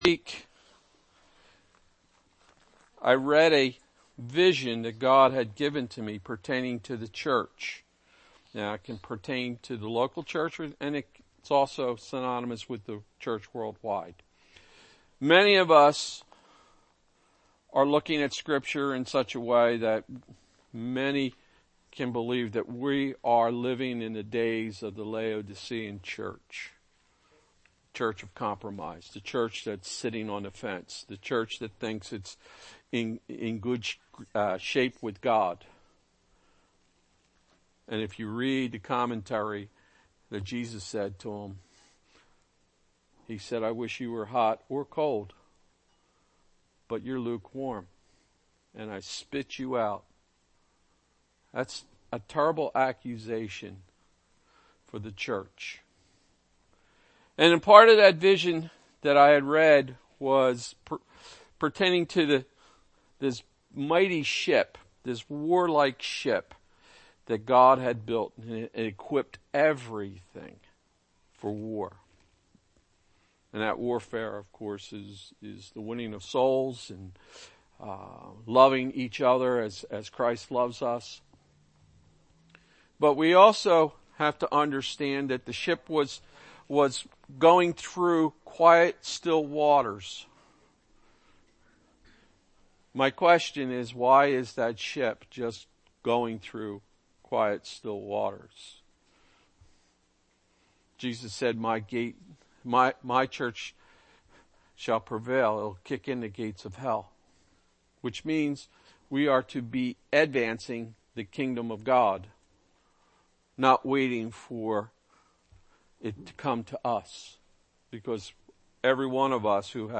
Sunday Service Developing_Radical_Thinking July 17, 2022 What is consuming your thoughts at this very moment?Is it the war in Ukraine?